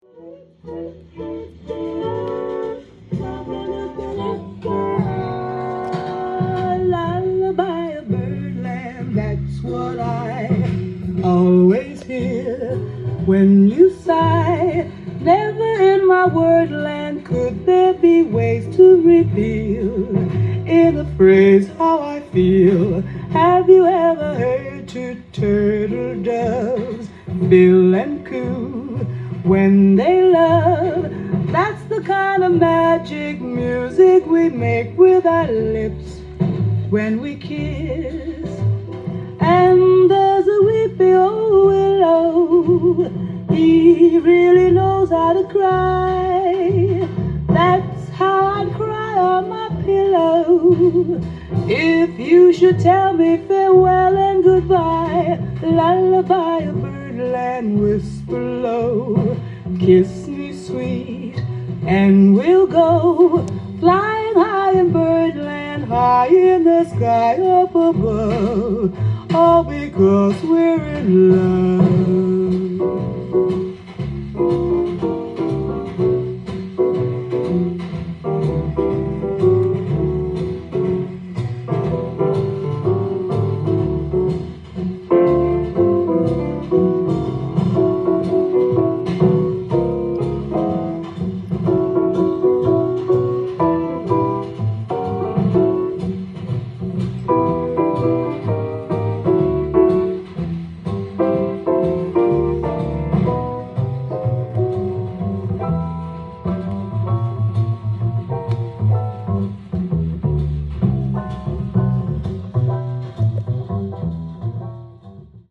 ジャンル：JAZZ-VOCAL
店頭で録音した音源の為、多少の外部音や音質の悪さはございますが、サンプルとしてご視聴ください。